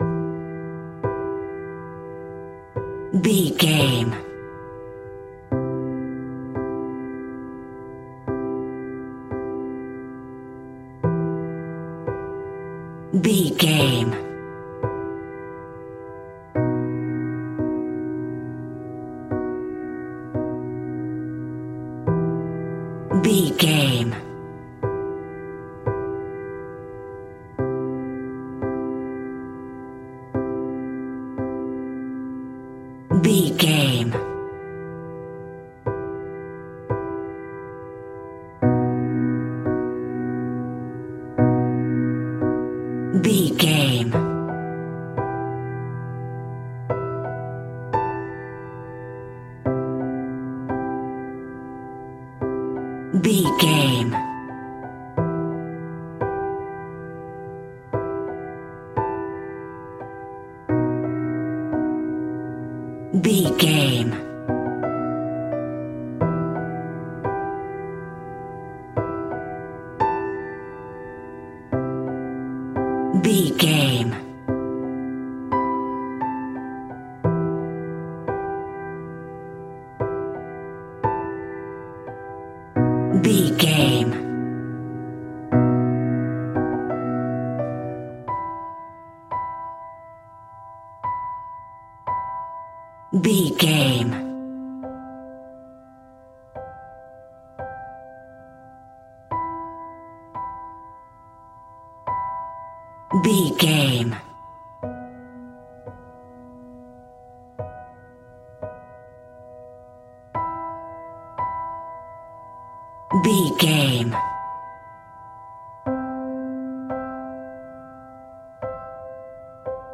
Simple and basic piano music in a major key.
Regal and romantic, a classy piece of classical music.
Aeolian/Minor
romantic
soft